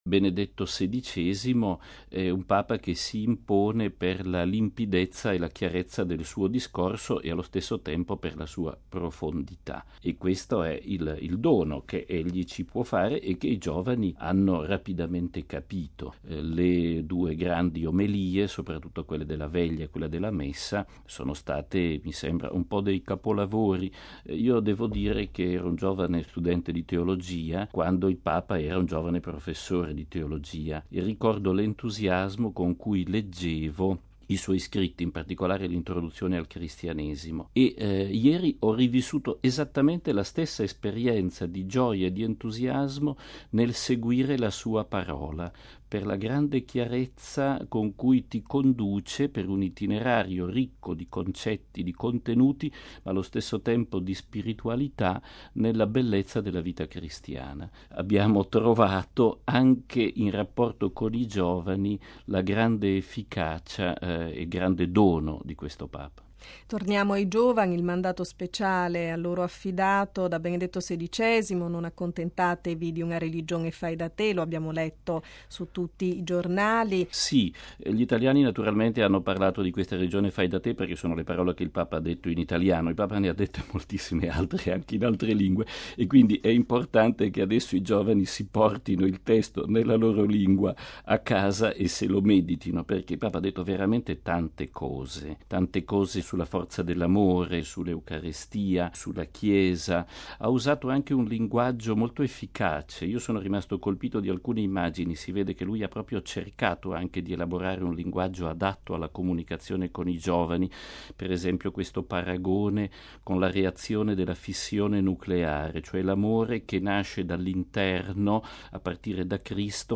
Gmg di Colonia. Il commento di Padre Federico Lombardi, direttore dei programmi della Radio Vaticana